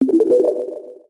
Elixir Collect Clash Of Clans Sound Effect Free Download